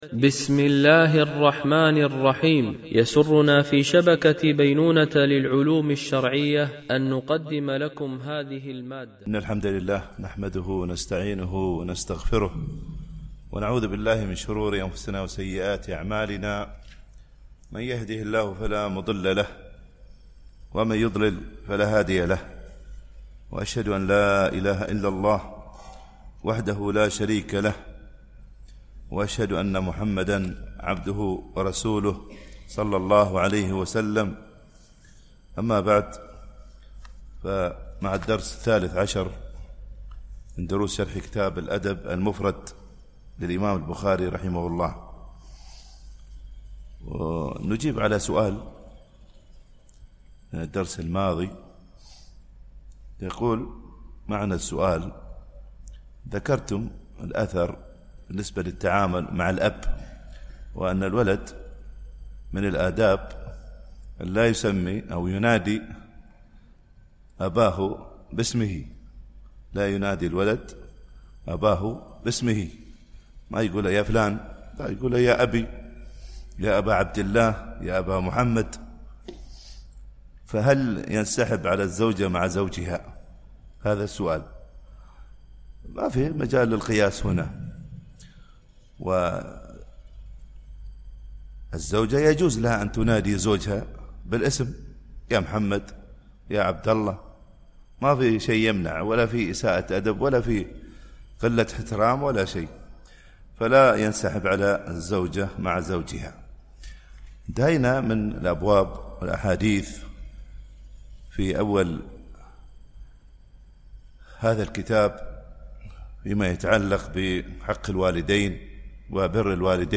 الشرح الثاني للأدب المفرد للبخاري - الدرس13 ( الحديث 47-51 )